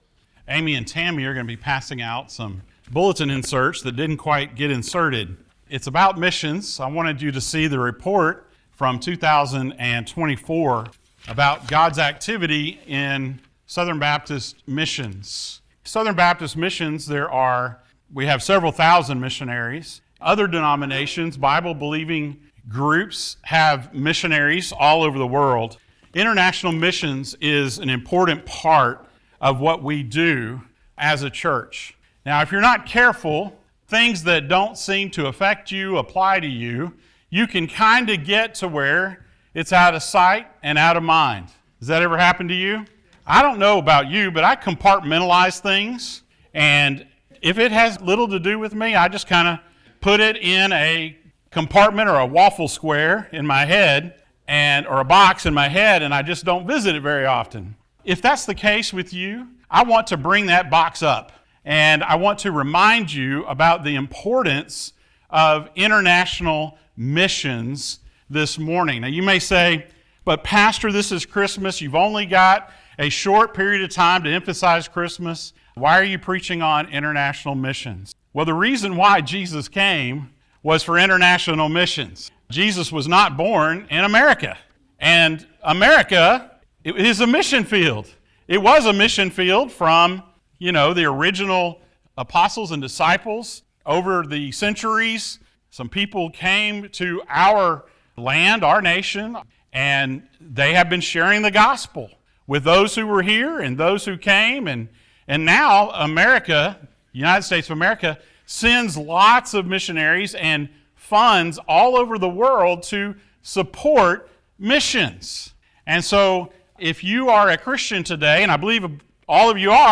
Passage: Acts 1:8, Matthew 28:19-20 Service Type: Sunday Morning